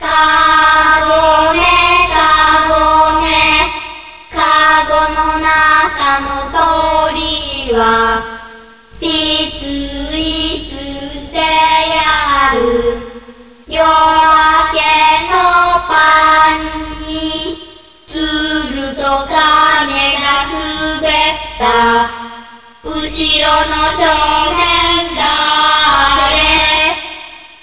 - A child's play song